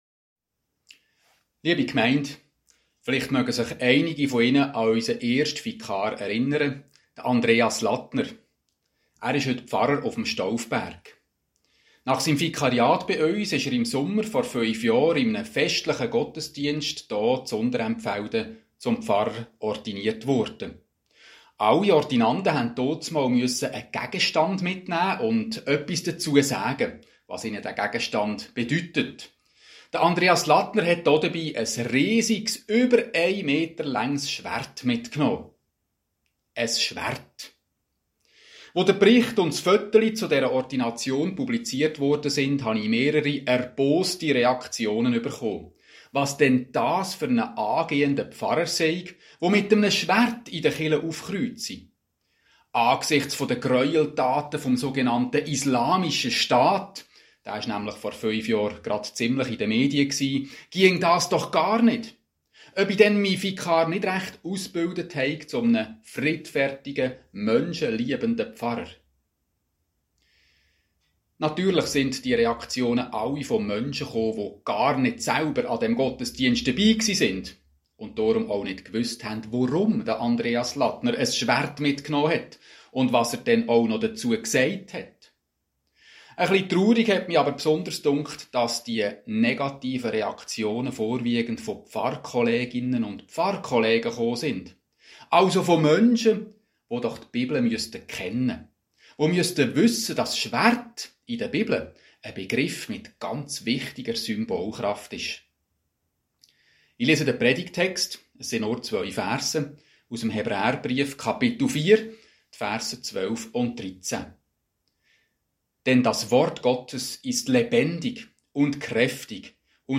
Predigt Predigt vom Sonntag, 28.